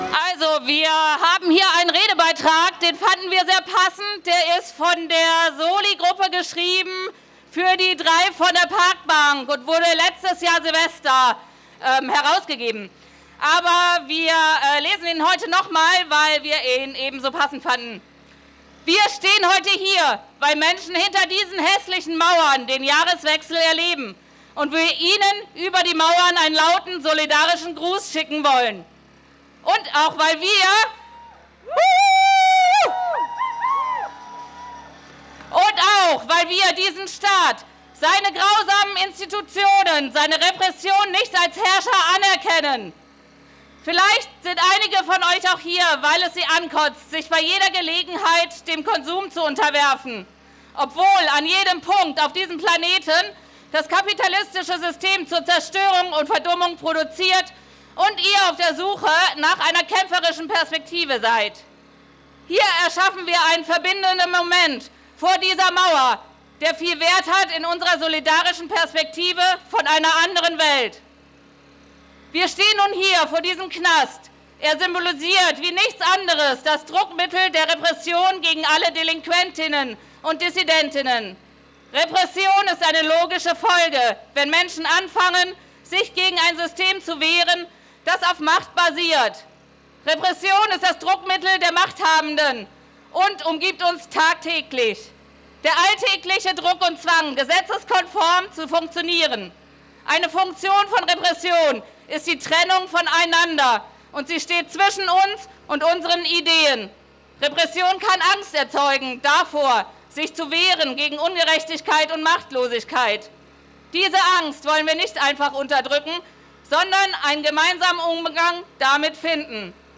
Vorgezogen, mit etwas weniger Feuerwerk, aber dennoch laut: Etwa 100 Menschen demonstrieren gegen Knäste
Kundgebung am Knast